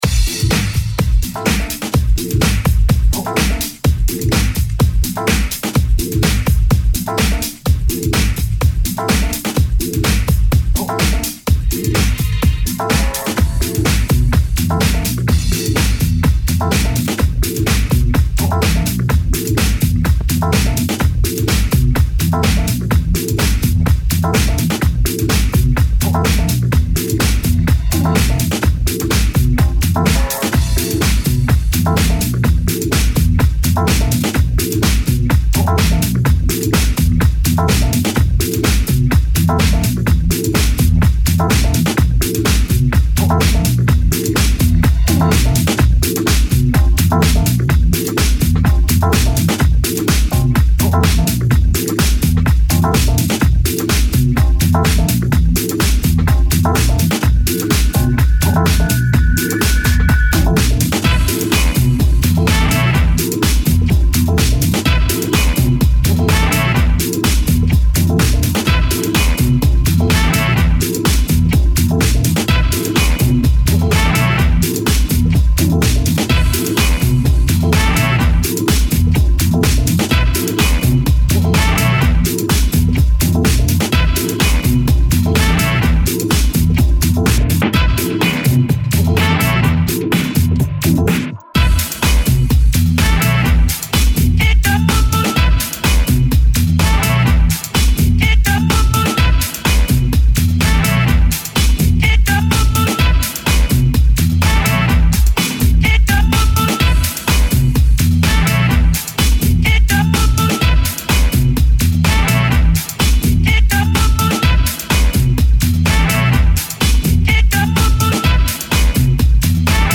Electronic dance music re-makes or re-mixes